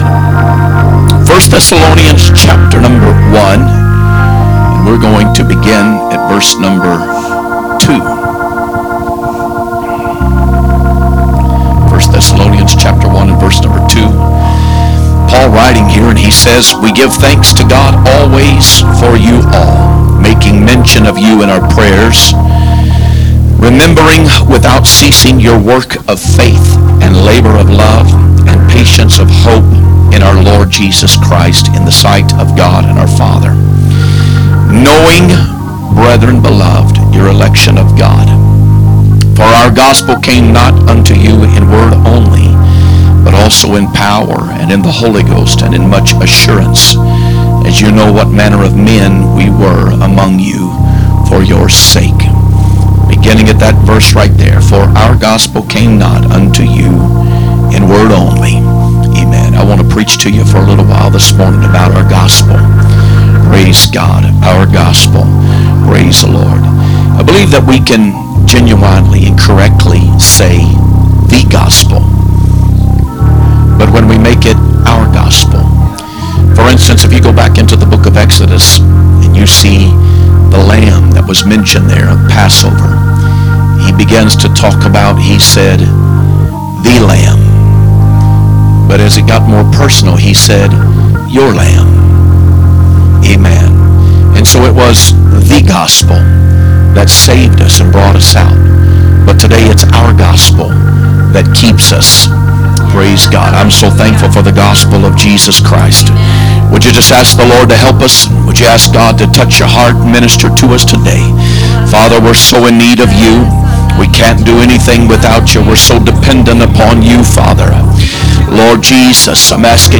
Sunday AM Preaching